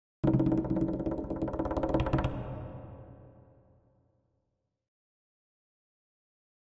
Drums Percussion Danger - Fast Drumming On A Medium Percussion 3